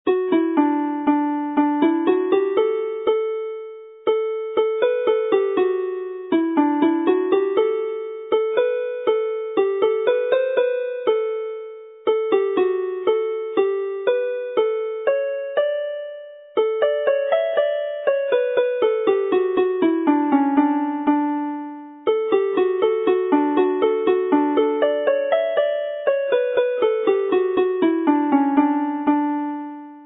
canu + offeryn
in D